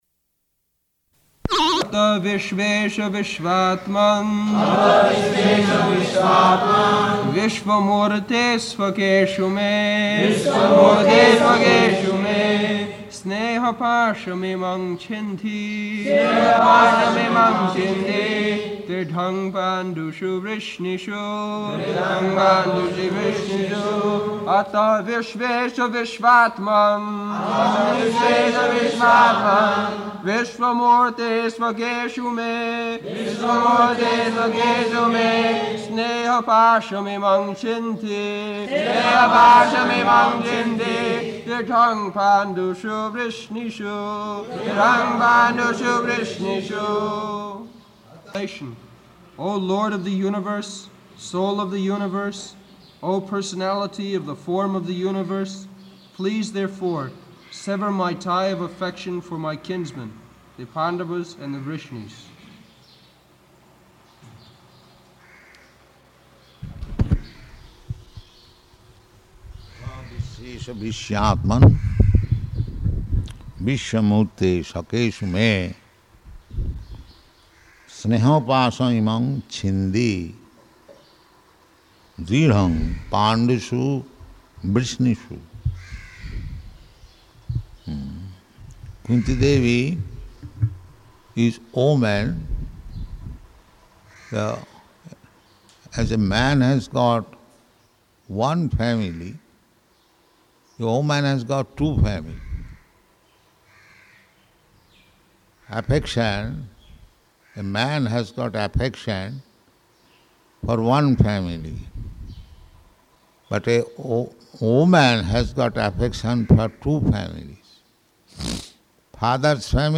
October 21st 1974 Location: Māyāpur Audio file
[leads chanting of verse, etc.] [devotees repeat]